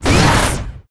launch_pri_firev1a.wav